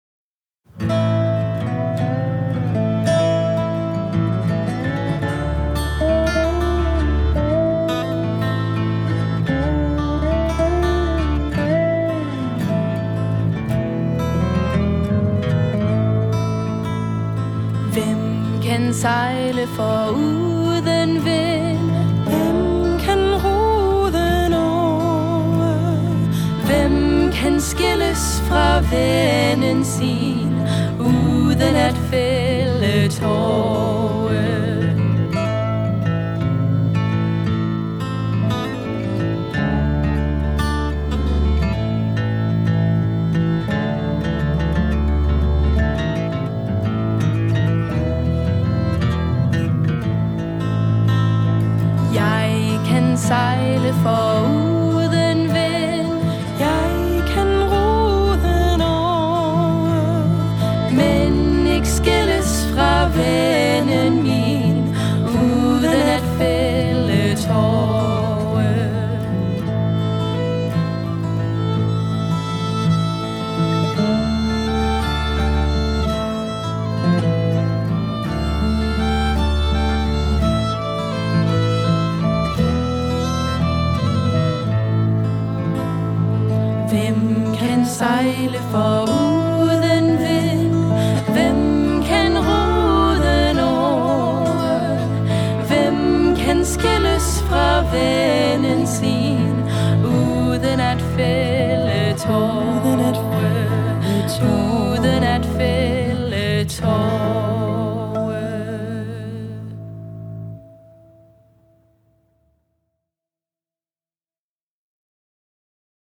This is a traditional Danish folk song describing the weight of loosing a loved one.